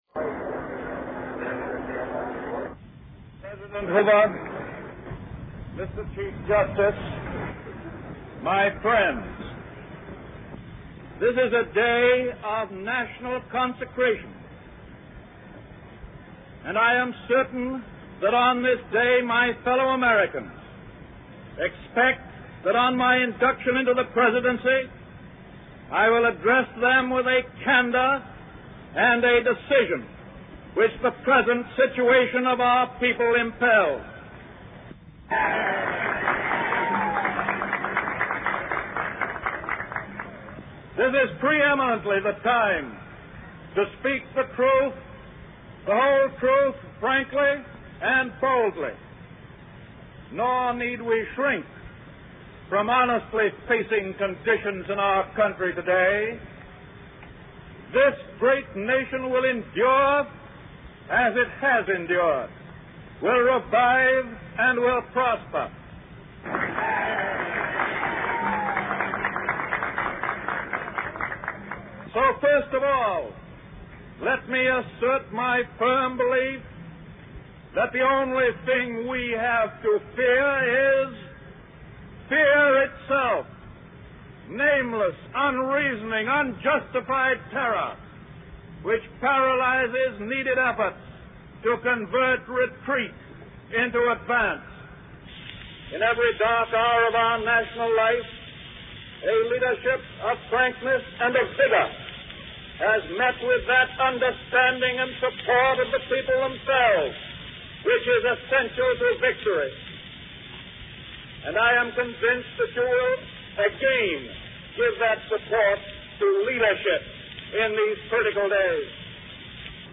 Franklin Delano Roosevelt: First Inaugural Address